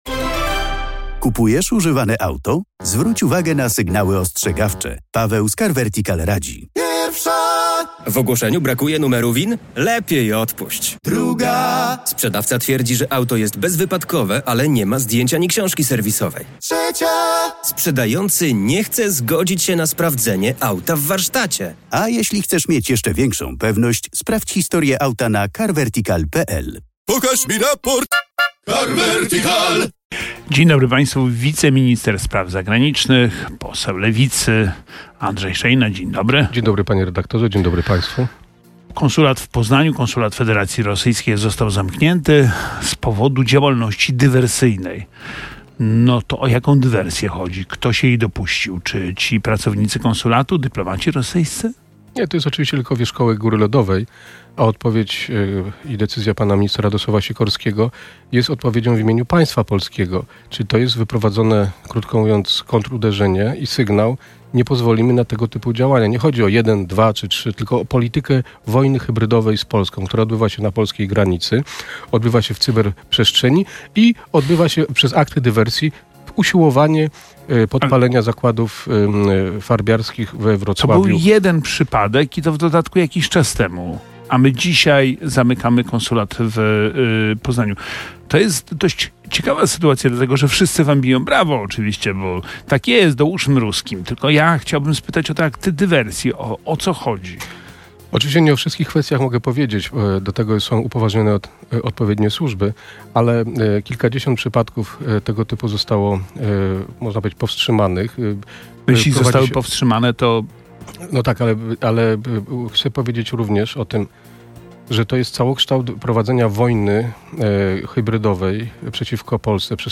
Odsłuchaj starsze transmisje RMF FM!
08:00 Fakty i Poranna rozmowa w RMF FM - 24.10.2024